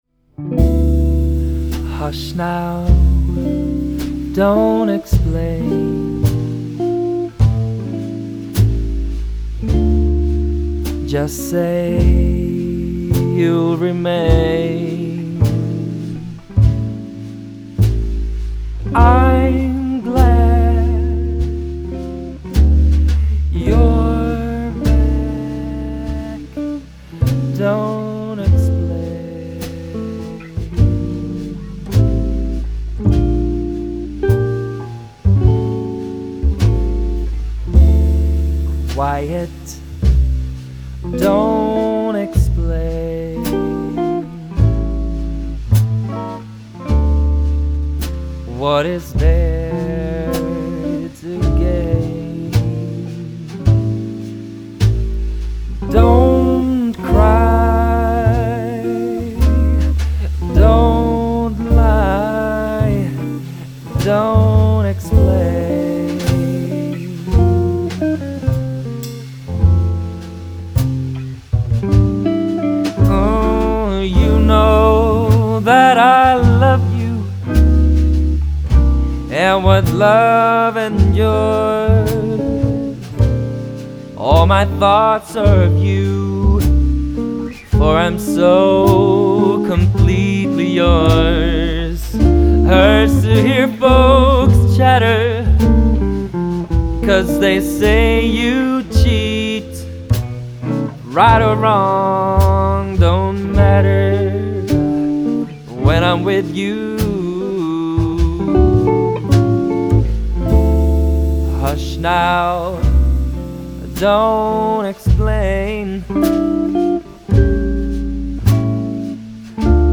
Jazzkvartetten
elegant klassisk vokaljazz